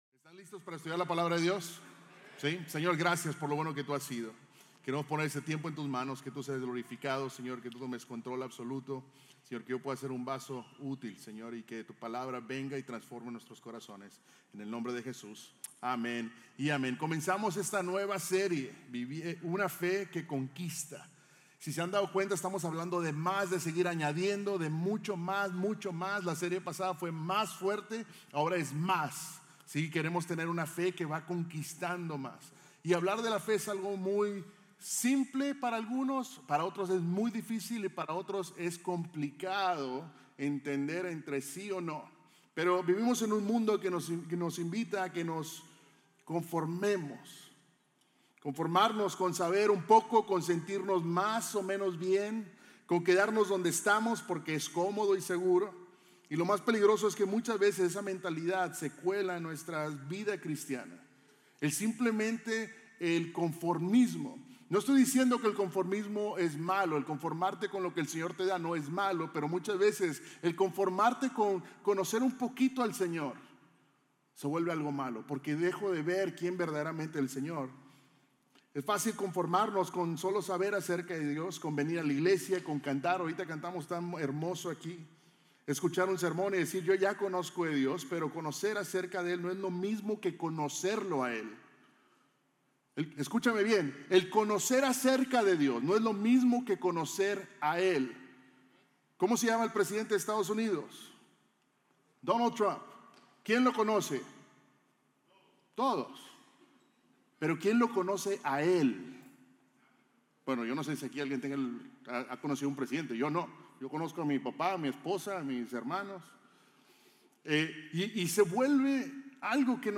Un mensaje de la serie "Evidencias - JV ."